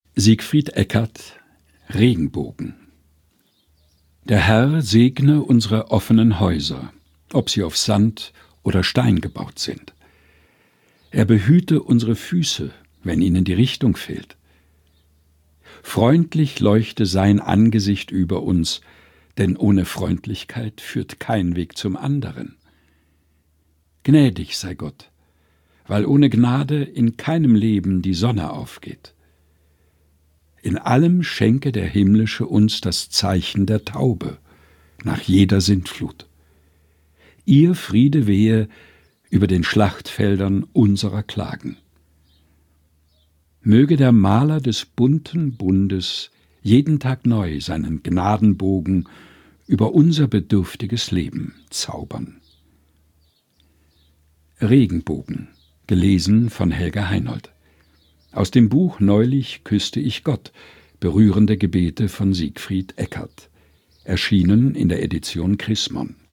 Texte zum Mutmachen und Nachdenken - vorgelesen von